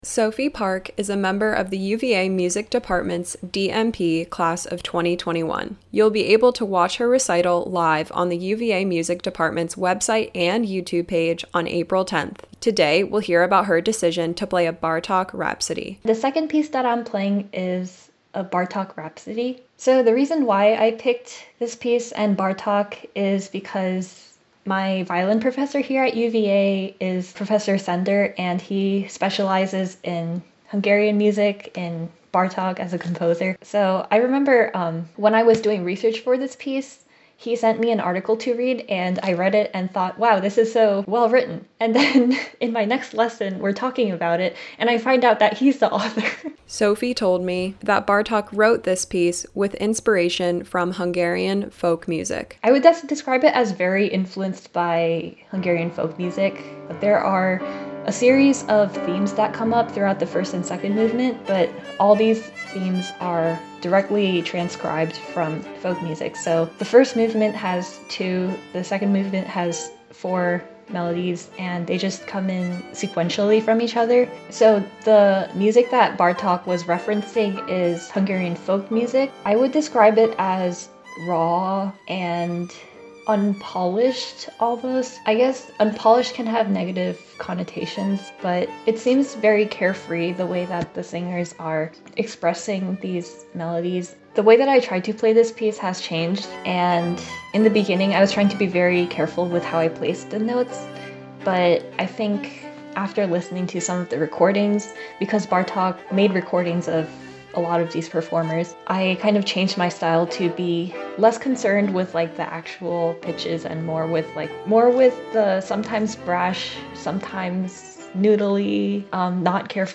Classical Interviews
These interviews air as part of WTJU’s Classical Sunrise, weekday mornings from 7-9am.